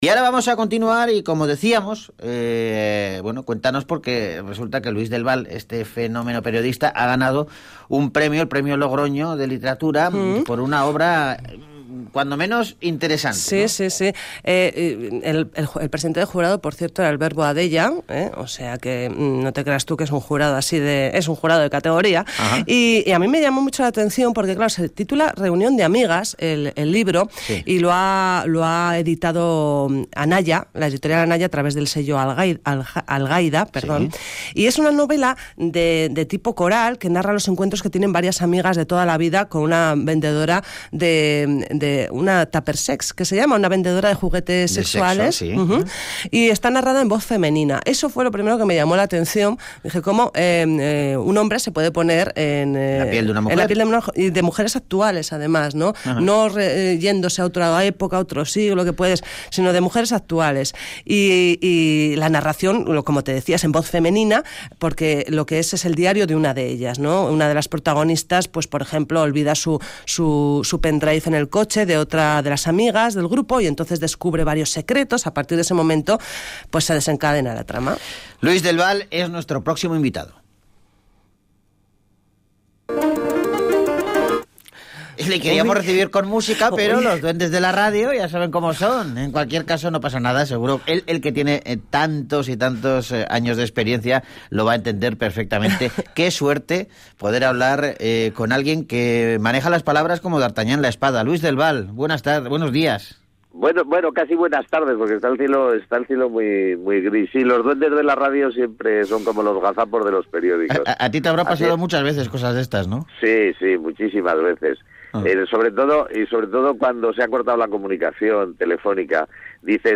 Luis del Val y su 'Reunión de amigas' | Entrevista en Radio Vitoria